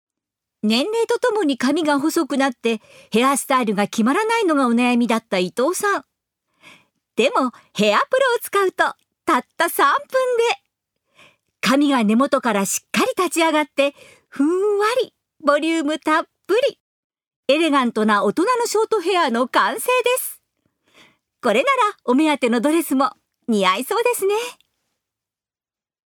1. ナレーション１